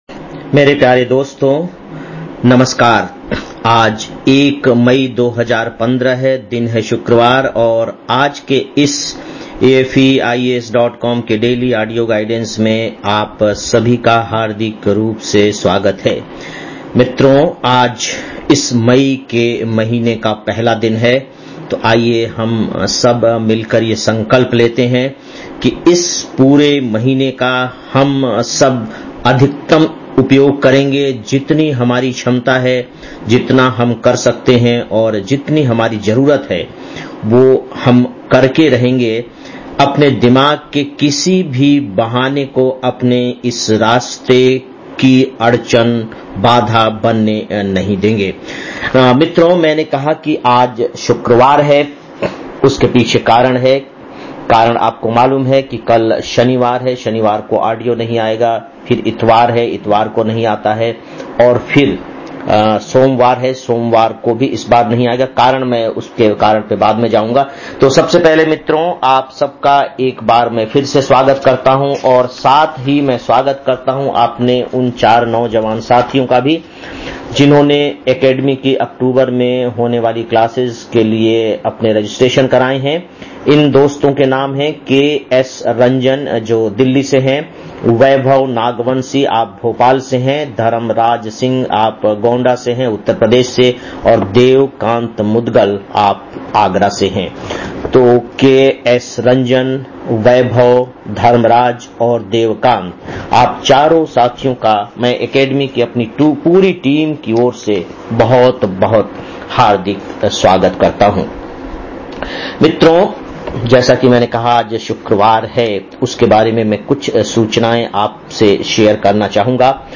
01-05-15 (Daily Audio Lecture) - AFEIAS